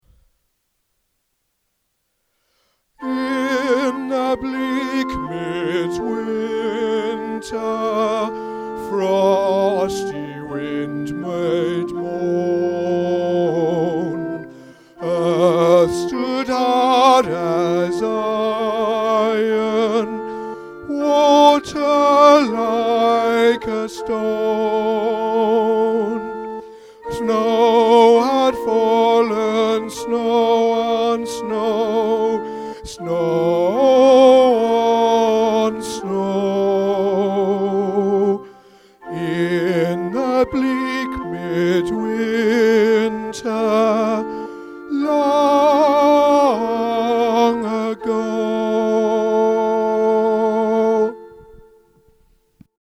In The Bleak Midwinter – Tenor | Ipswich Hospital Community Choir
In-The-Bleak-Midwinter-Tenor.mp3